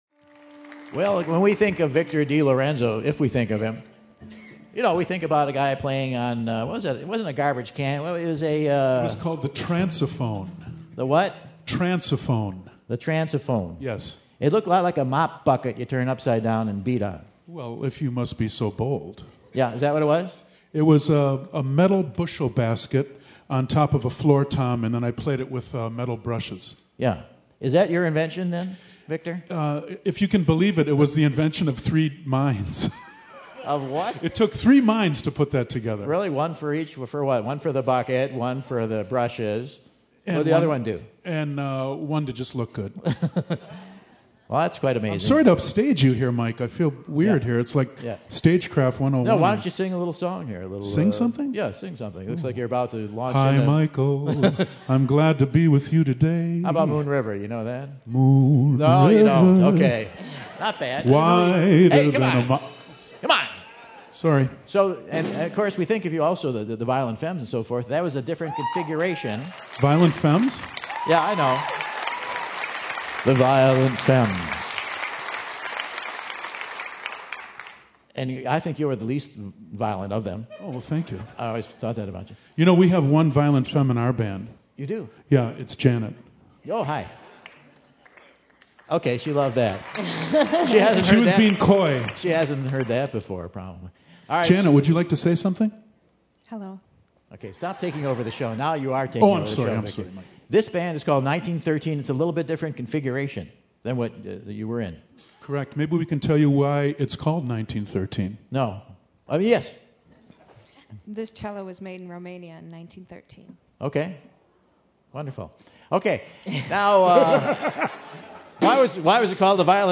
cellist
on percussion
on drumset
chamber rock band